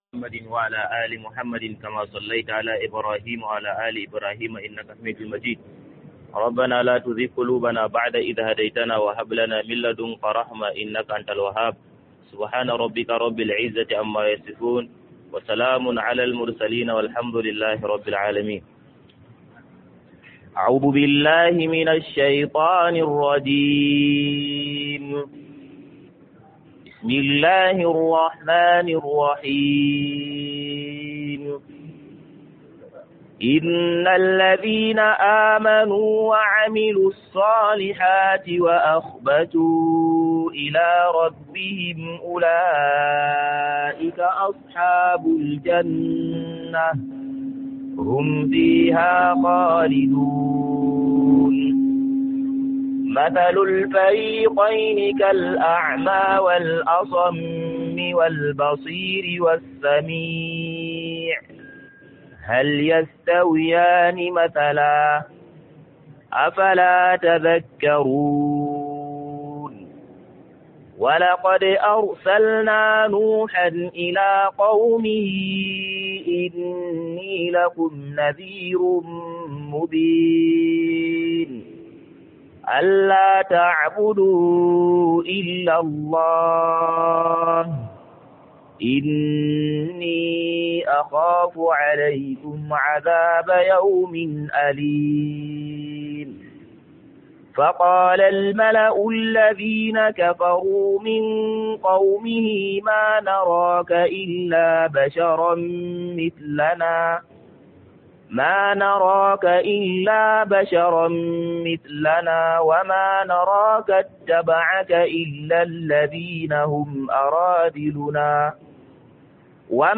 Ranar Alkiyama a Yola - MUHADARA